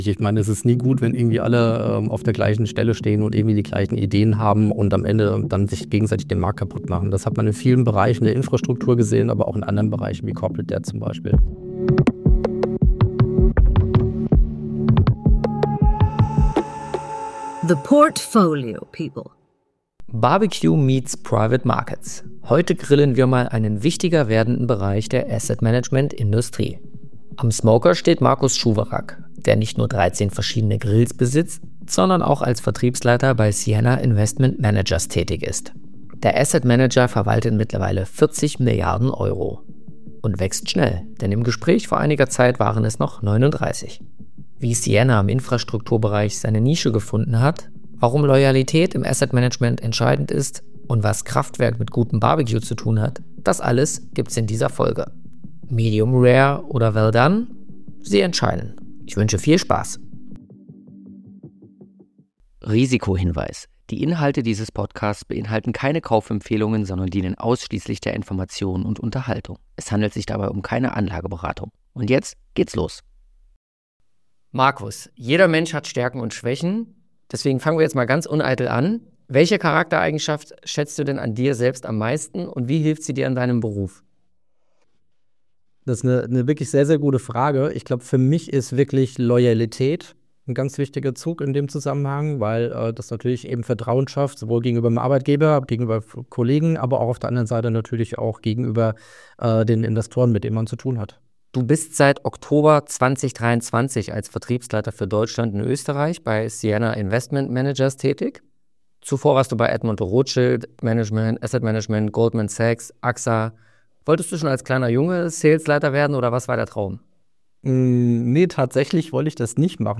Ein spannendes Gespräch über nachhaltige Investments, die Zukunft der Asset Management Branche und die perfekte Work-Life-Balance - inklusive 13 verschiedener Grills.